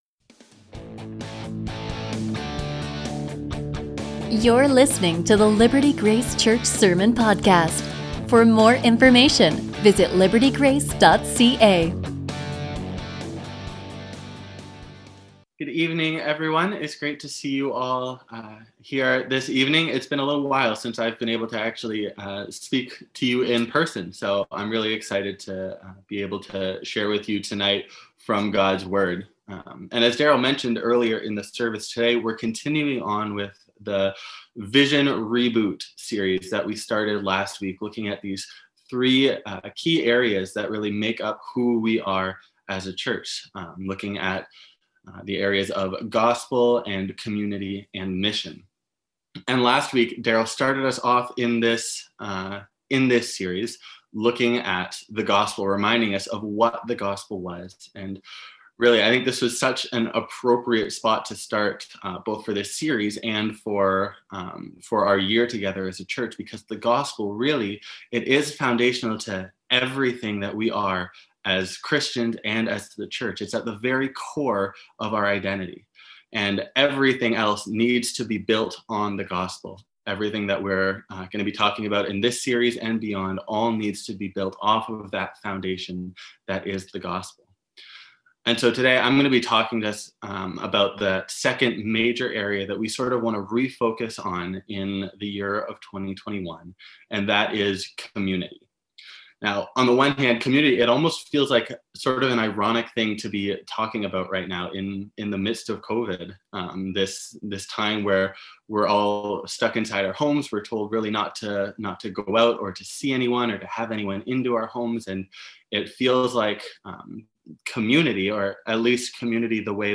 The gospel creates community. A sermon from Romans 12:9-21